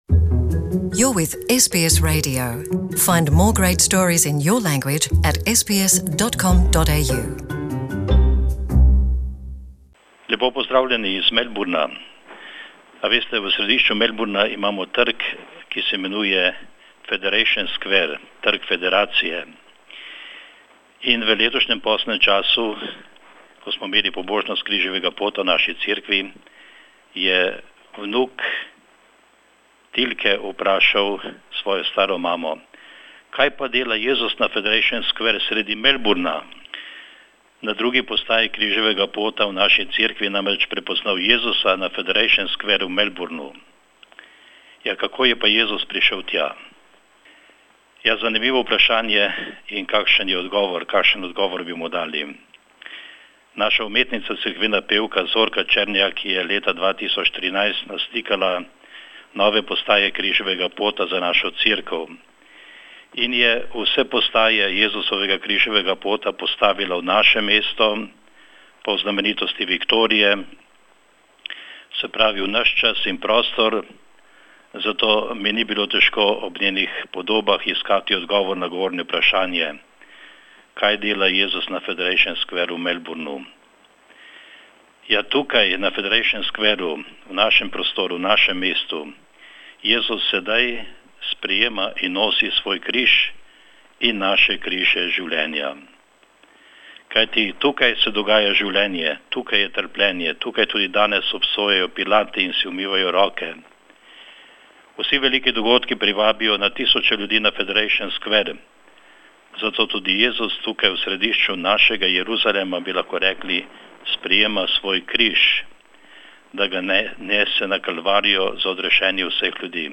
Velikonočna sporočila slovenskih patrov v Avstraliji